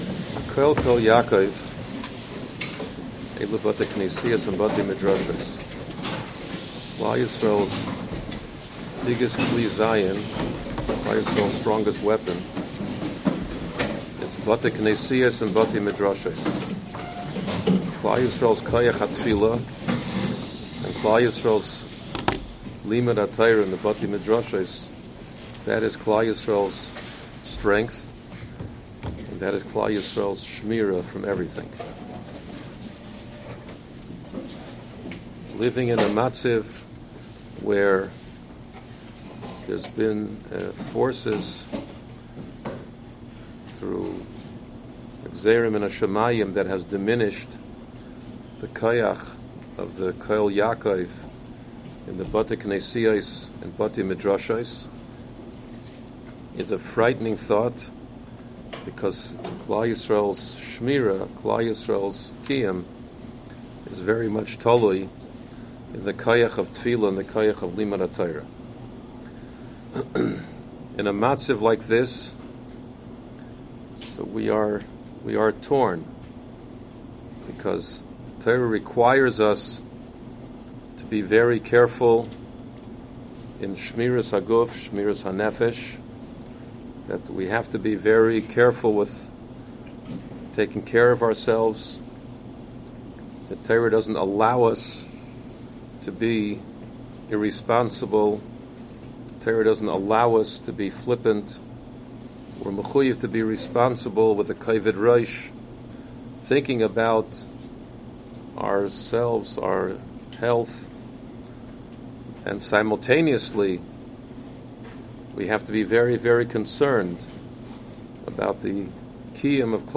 Special Lecture - Ner Israel Rabbinical College
A schmooze given in Yeshiva on Monday night